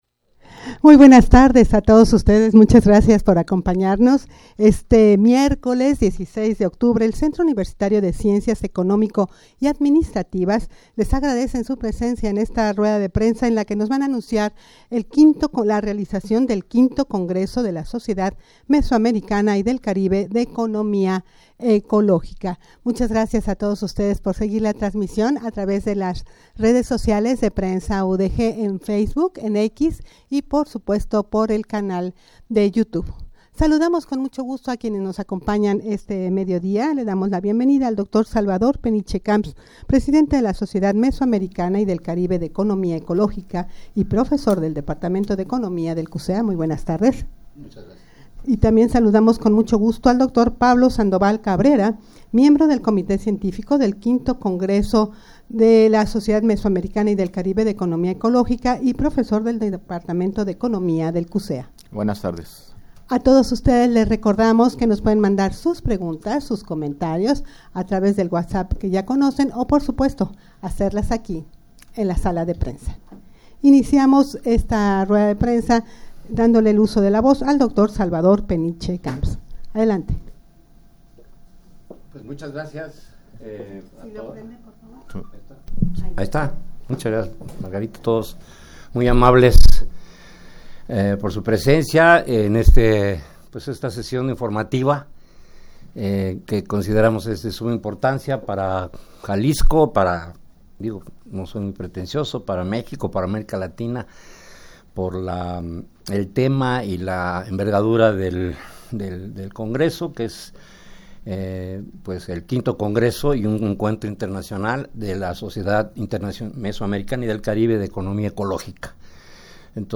Audio de la Rueda de Prensa
rueda-de-prensa-para-anunciar-el-v-congreso-de-la-sociedad-mesoamericana-y-del-caribe-de-economia-ecologica.mp3